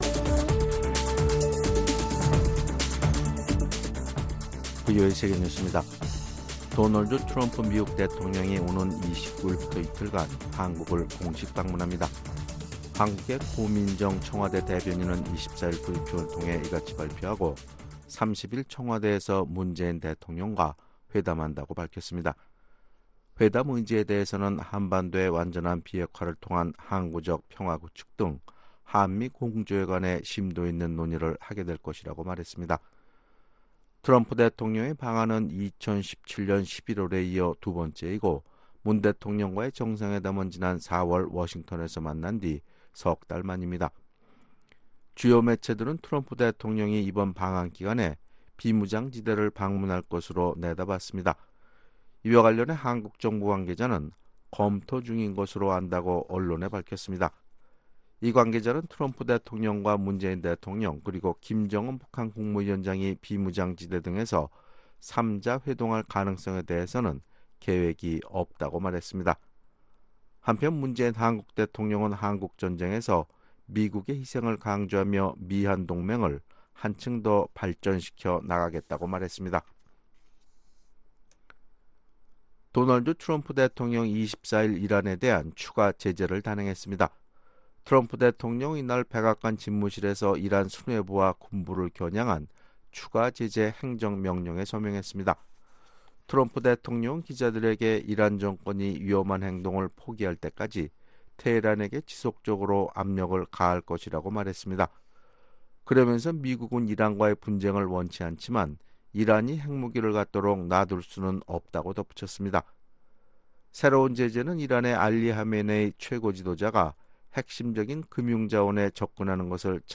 VOA 한국어 아침 뉴스 프로그램 '워싱턴 뉴스 광장' 2019년 6월 25일 방송입니다. 마이크 폼페오 미국 국무장관이 미-북 실무 협상 재개에 대해 낙관적인 견해를 밝혔습니다. 일본에서 열리는 G20 정상회의를 전후한 트럼프 대통령과 한반도 주변국 정상들의 연쇄 회동이 미-북 비핵화 협상 재개에 분수령이 될 전망입니다.